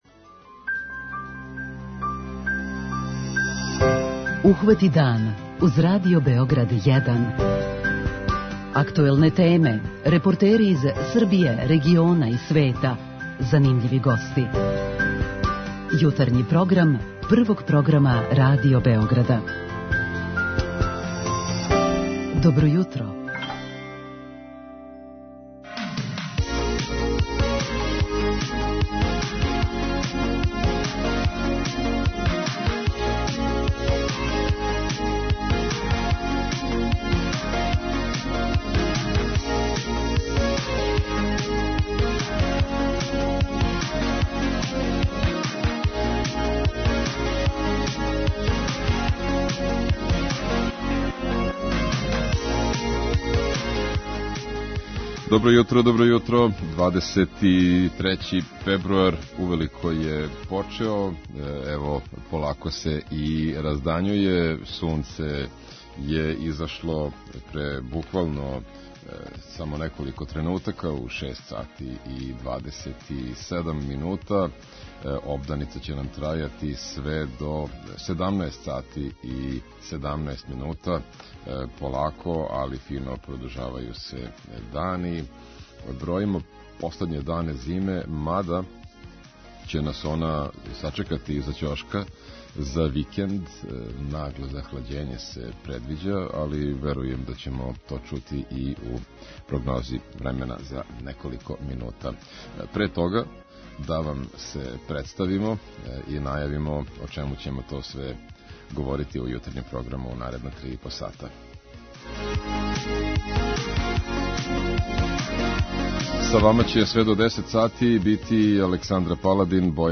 Ова питања поставићемо слушаоцима у нашој редовној рубрици 'Питање јутра', поводом саопштења Удружења педијатара Србије које се заложило за што скорије увођење ове мере из здравствених разлога.